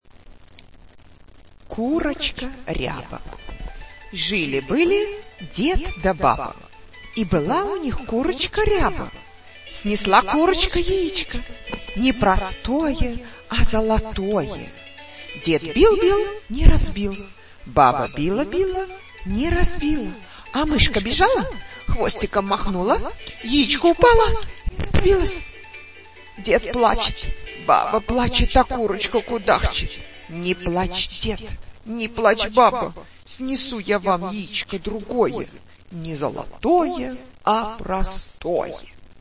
AudioLivreRusse Contre Russe Kyrotchka Ryaba MP3